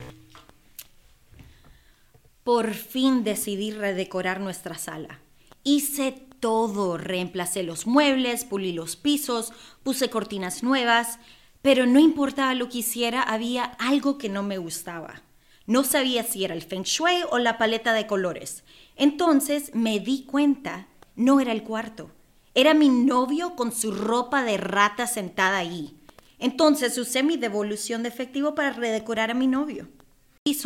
Voiceover Samples:
Discover Card English VO Sample